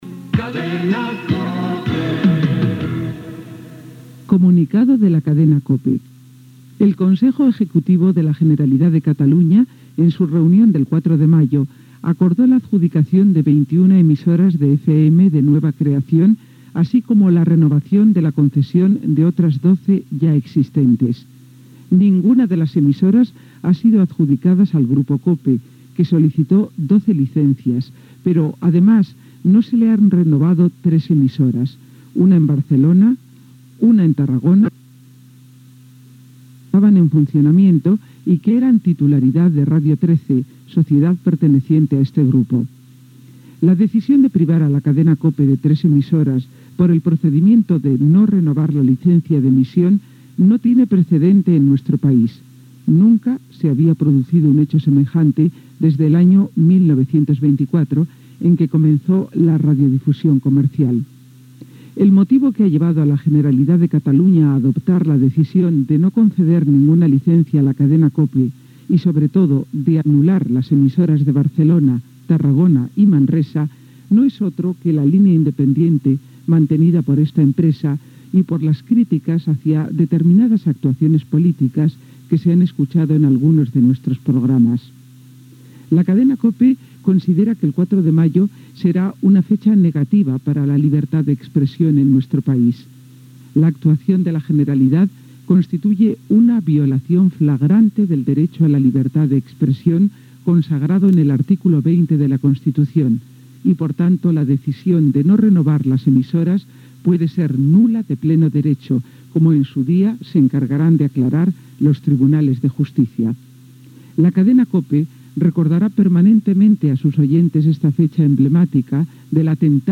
Careta de "Las noticias de las 12", Reaccions a la revocació de les llicències, amb declaracions de Mariano Rajoy i Xavier Trias.
Informatiu